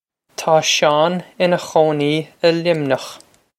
Pronunciation for how to say
Taw Shawn inna khoh-nee ih Lim-nyokh.
This is an approximate phonetic pronunciation of the phrase.